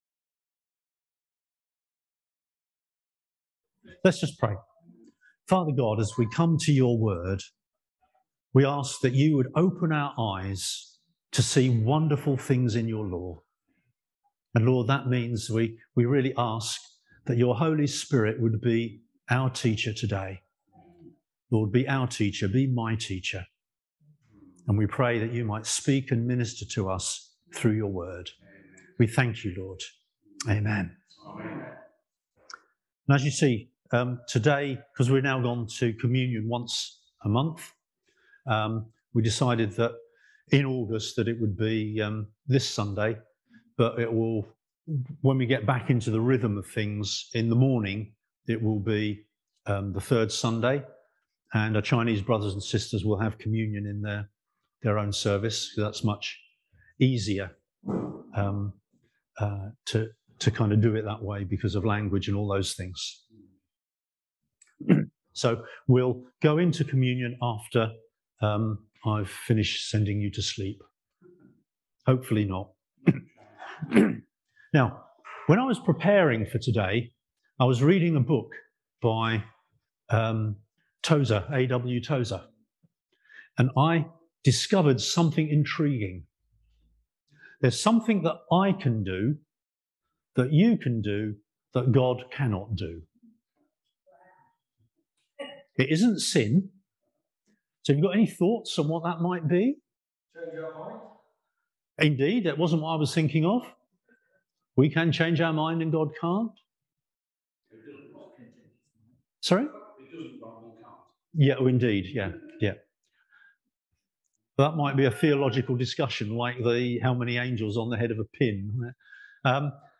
Service Type: Sunday Service Topics: Holy Spirit , Jesus , Omniscience , Salvation